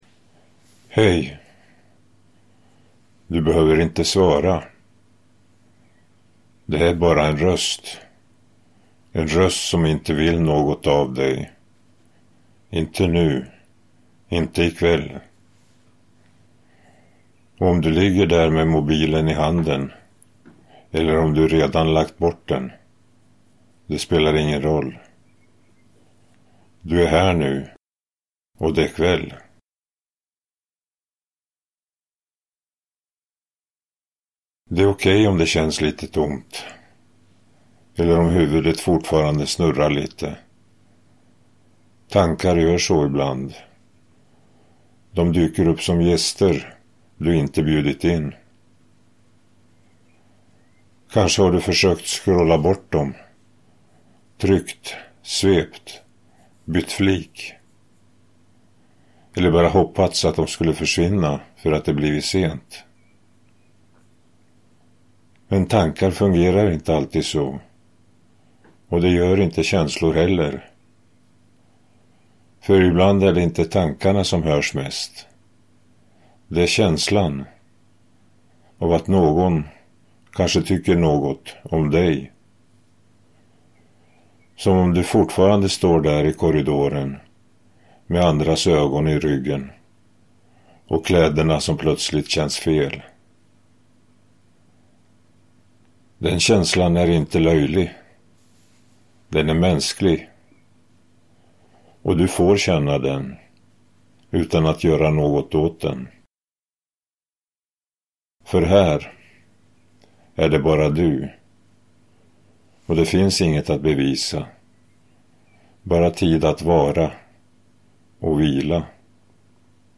När mobilen blev tyst – En sömnvänlig röst, insomningshjälp och kvällsguide för tonåringar – skapad med hjälp av AI och psykologi – Ljudbok
Bara en varm, sömnvänlig röst – som långsamt leder bort från dagen, in i kvällen, och vidare mot vila.
Med ett långsamt tempo, mjukt språk och varsamma pauser ger berättelsen tillåtelse att släppa taget – en mening i taget.